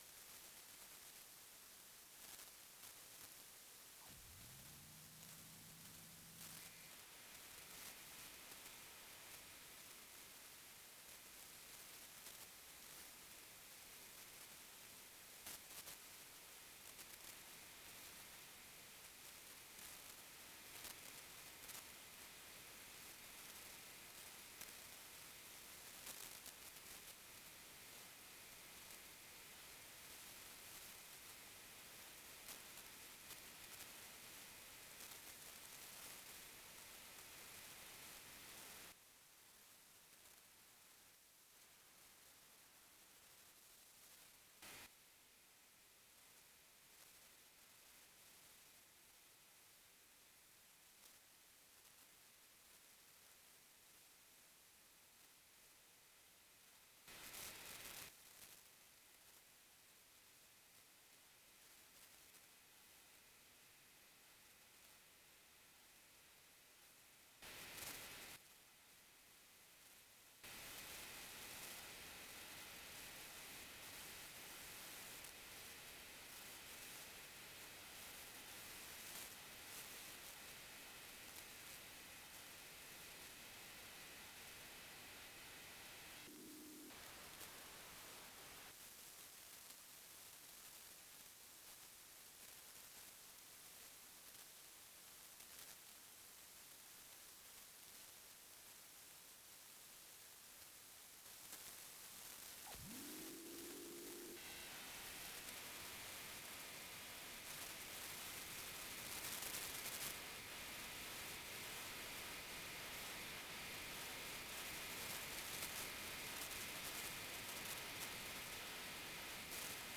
IQ Baseband Crudo